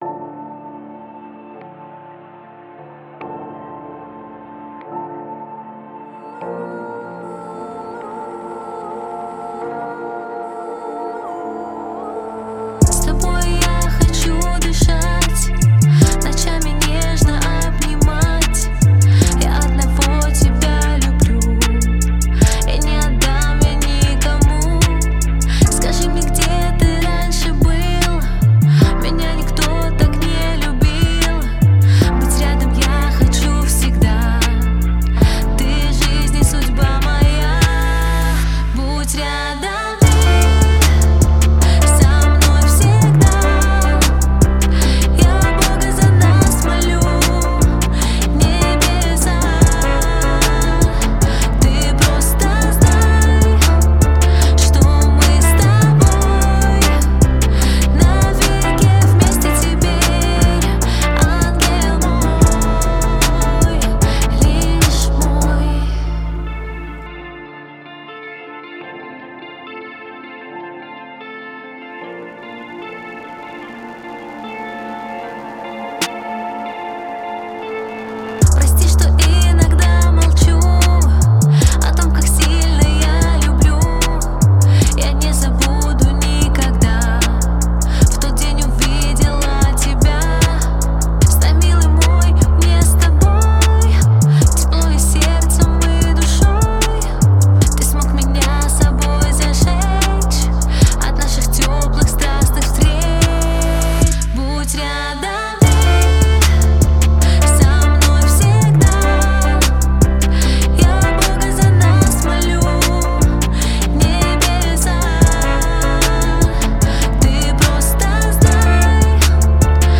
В ней звучат мелодичные мелодии и выразительный вокал